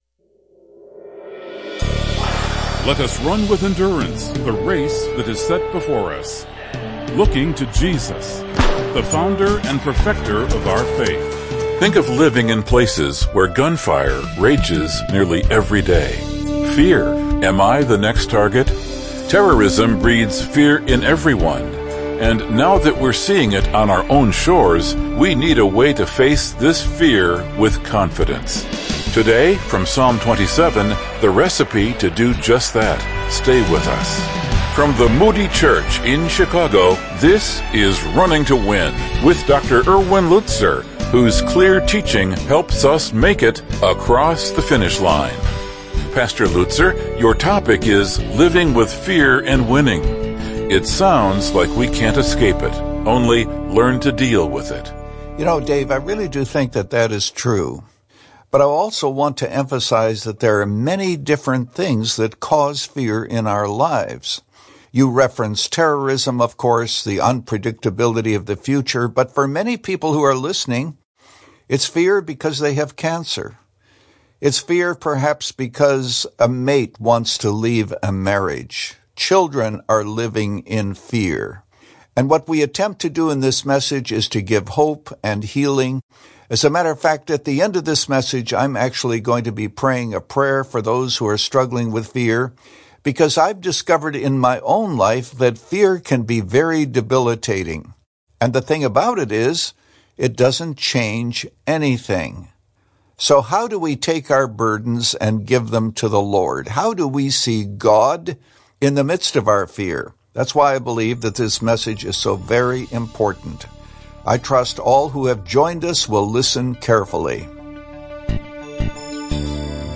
In this message from Psalm 27